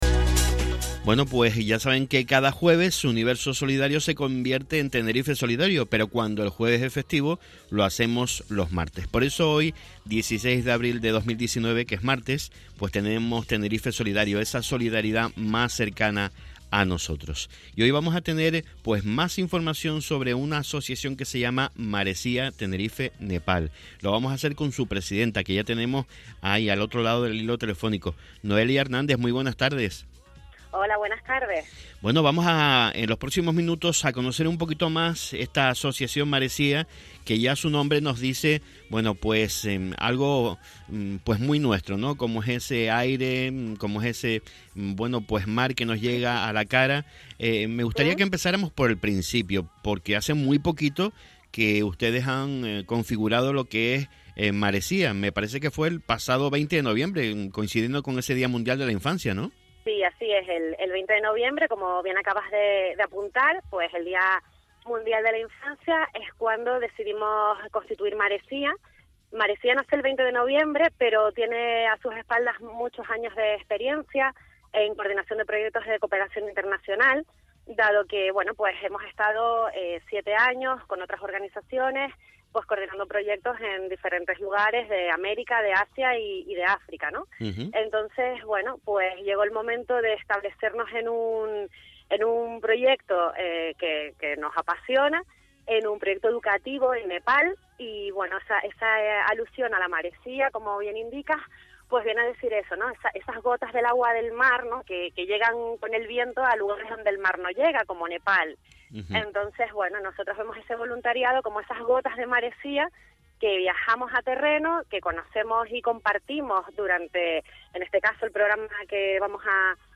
Aquí te dejamos el enlace con la entrevista donde podrás conocer todos los detalles de Maresía que te resumimos en 20 minutos.
entrevista-radio-el-dc3ada.mp3